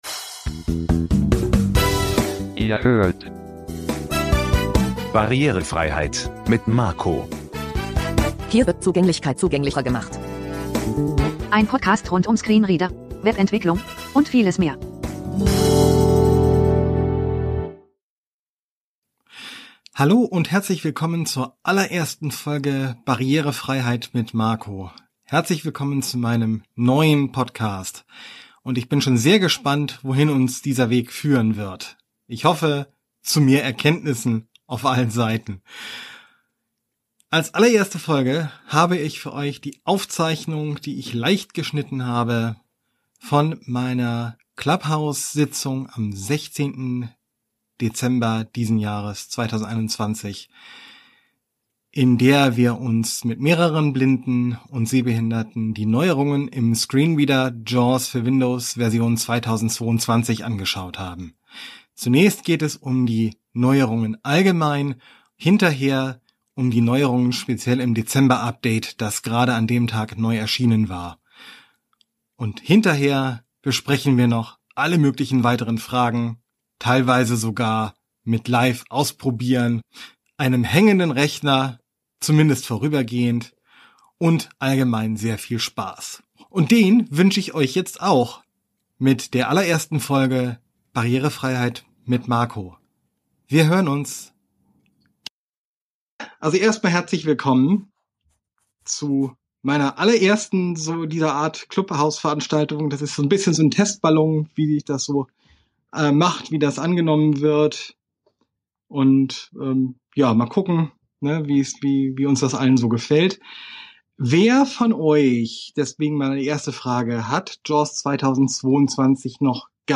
Um dieses und viele andere Neuerungen ging es bei einer Veranstaltung, die ich am 16.12.2021 auf der Plattform Clubhouse ausrichtete. Zum Auftakt meines neuen Podcasts gibt es hier eine leicht geschnittene Aufzeichnung dieser Veranstaltung.